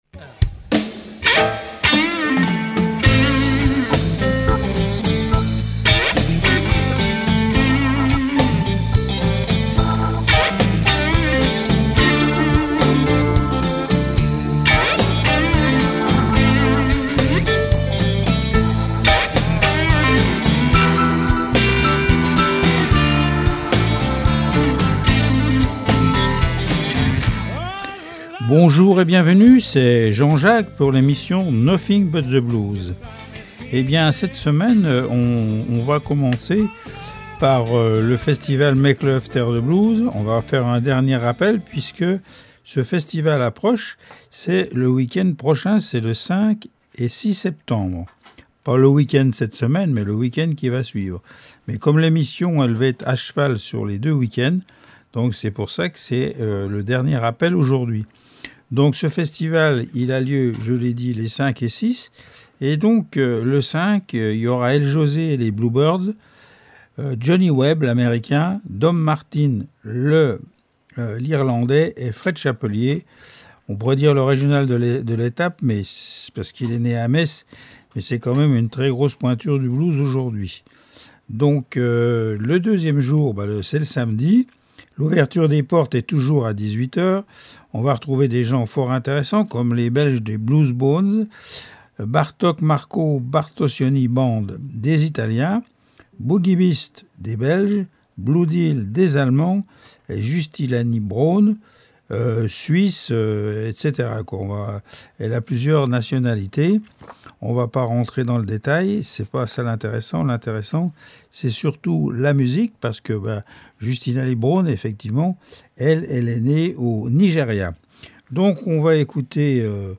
Du traditionnel au blues rock actuel.